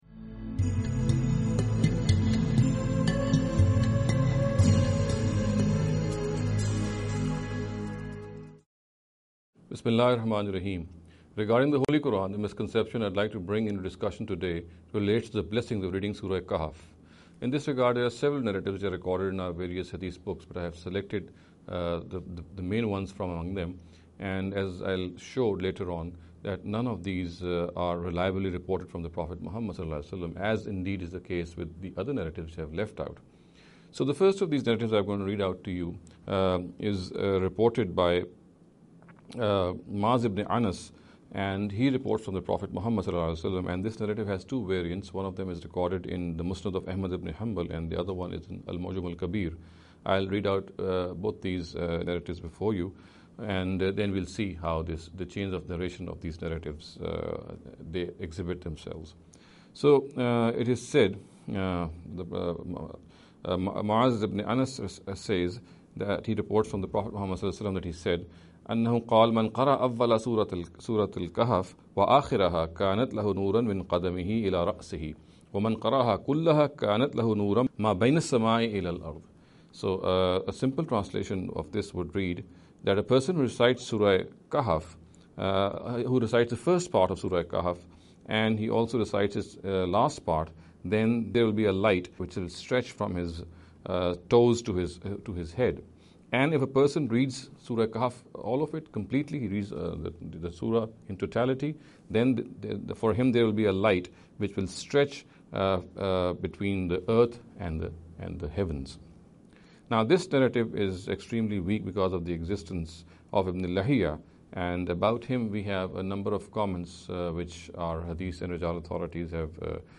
This lecture series will deal with some misconception regarding the Holy Quran. In every lecture he will be dealing with a question in a short and very concise manner.